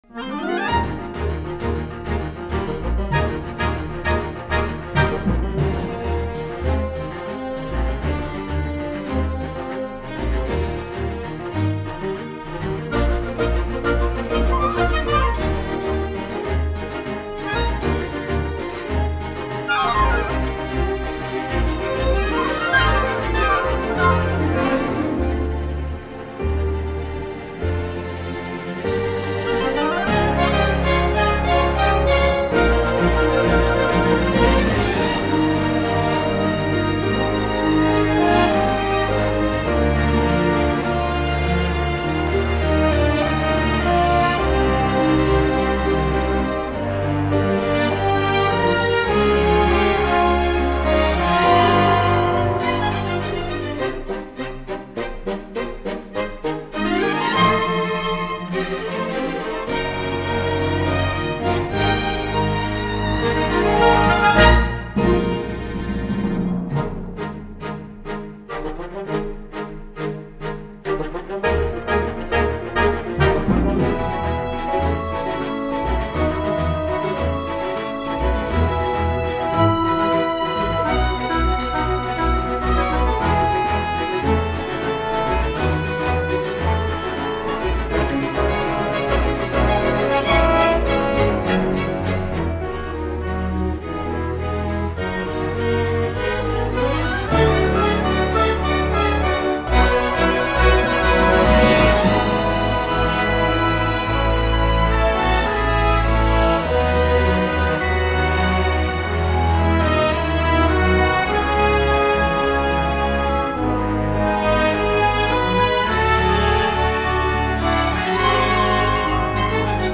Song with lyrics